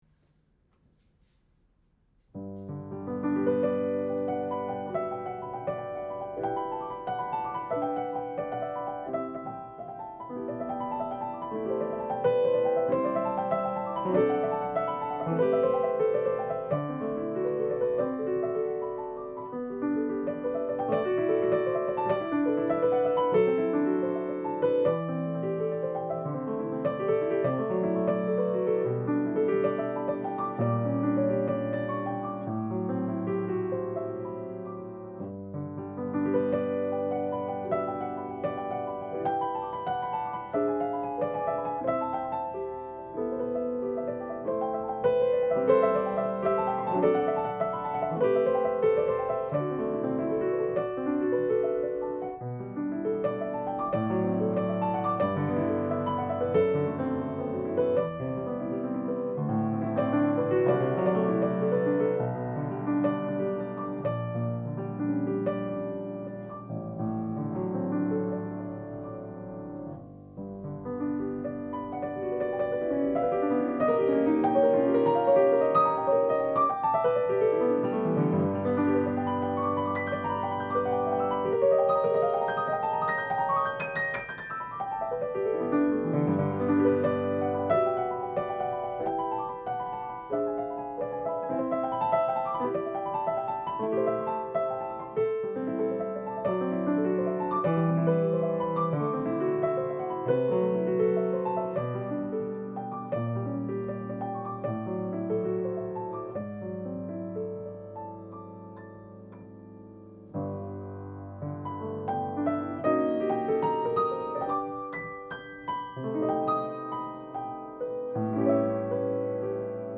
Piano playing